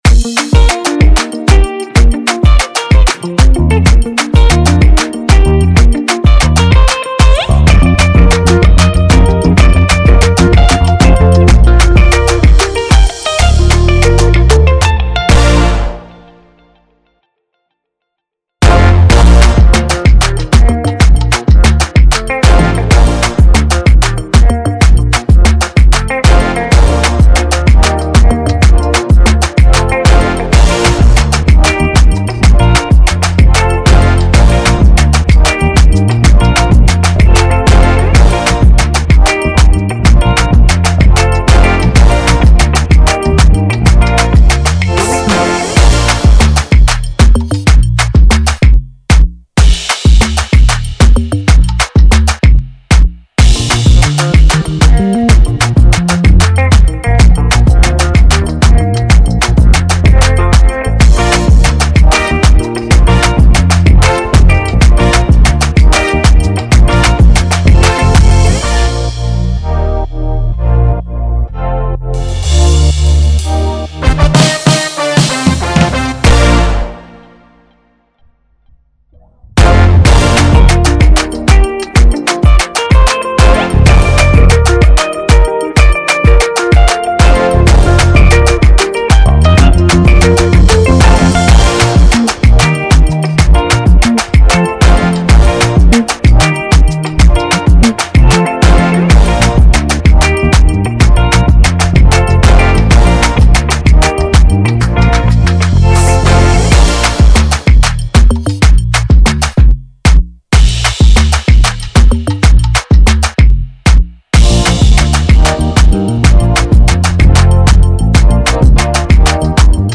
2024 in Dancehall/Afrobeats Instrumentals